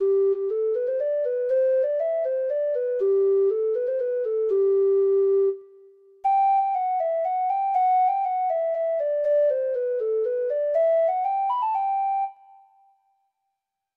Folk Songs from O' Neills Music of Ireland Letter T The Maid of Lismore (Irish Folk Song) (Ireland)
Free Sheet music for Treble Clef Instrument